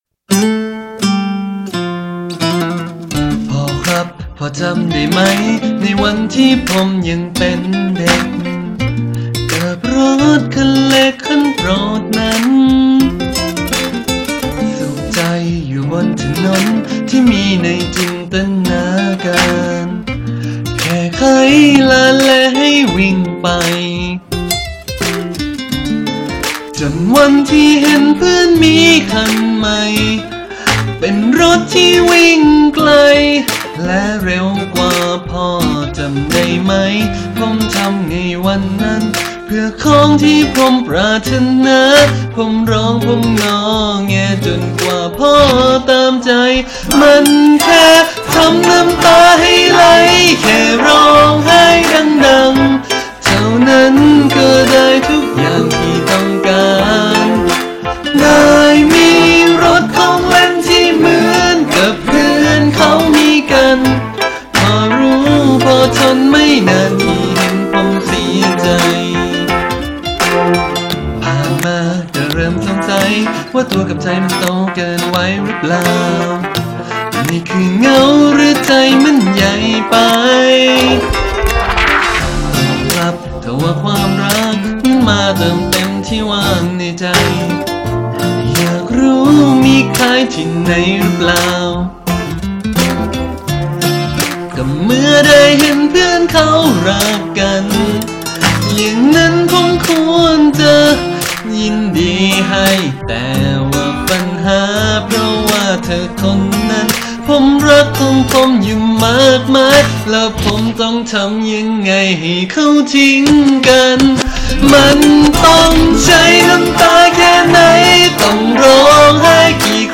คำเตือน อันนี้ cover นะครับไม่ใช่ต้นฉบับ